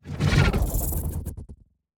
Robotic Notification 5.wav